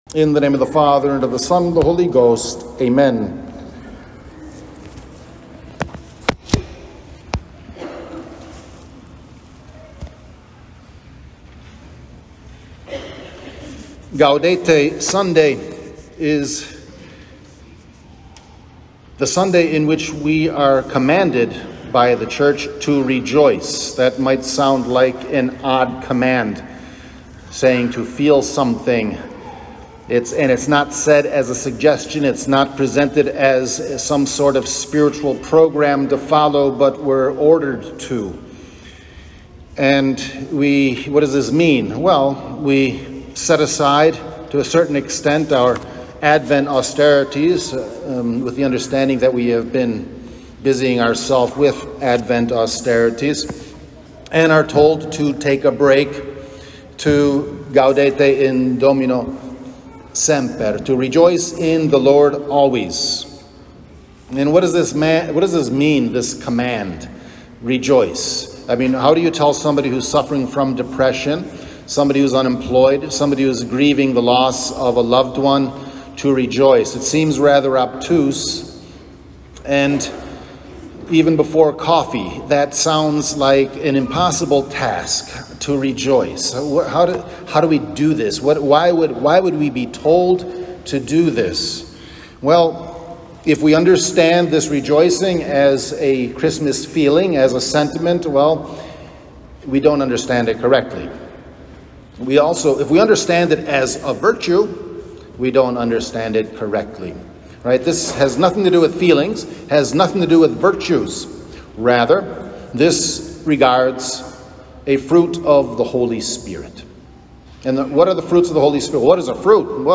Gaudete Sunday 2021 – Homily